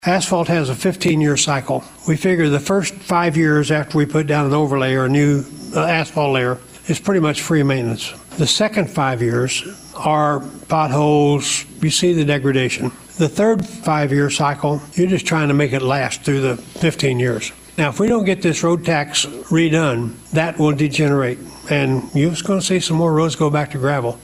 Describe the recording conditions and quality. The announcement was made at the St. Francois County State of the County address held Friday in the Cardinal Center at Mineral Area College.